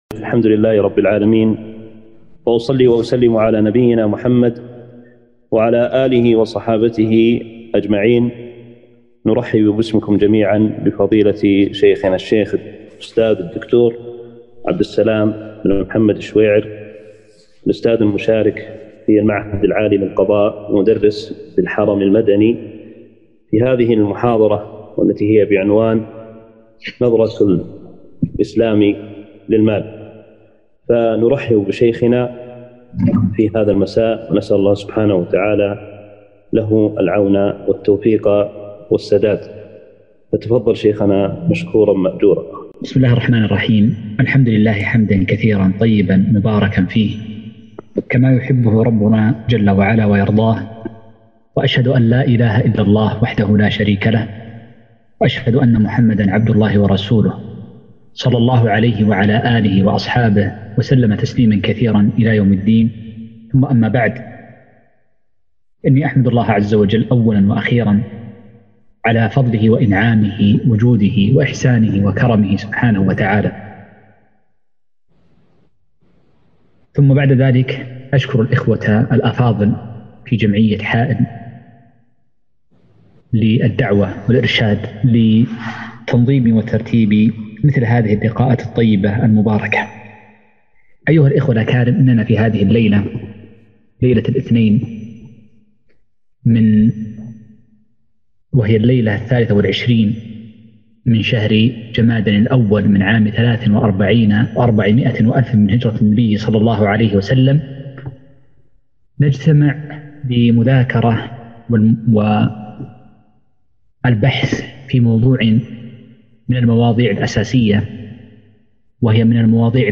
محاضرة - نظرية المالية فِقهًا